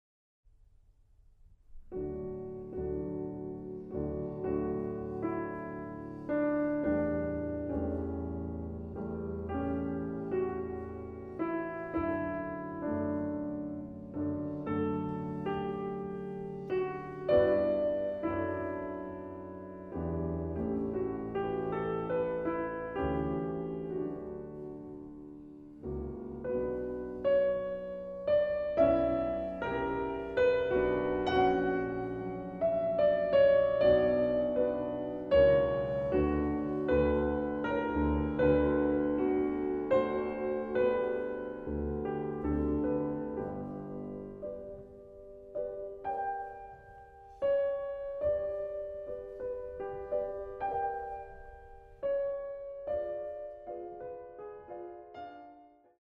arr. for 2 pianos